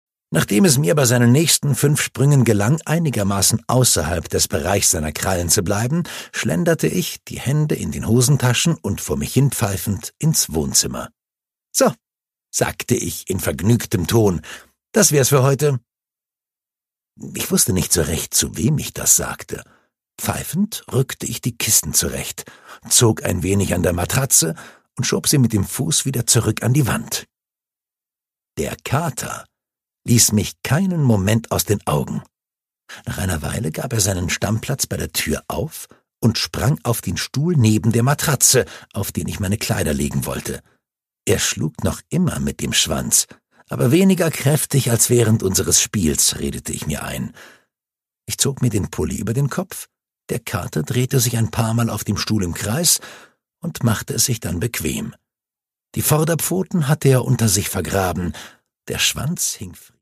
Produkttyp: Hörbuch-Download
Fassung: Autorisierte Lesefassung